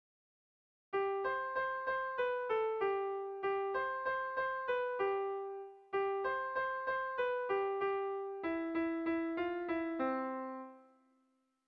Dantzakoa
A1A2